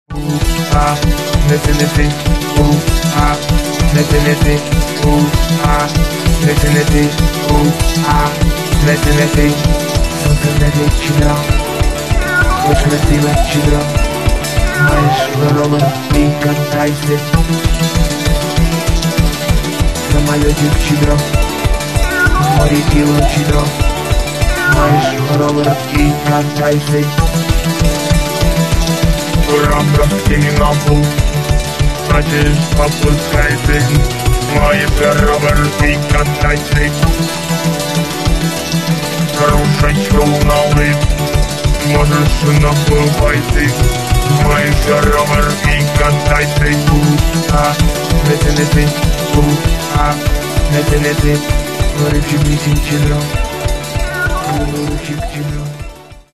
Рок и альтернатива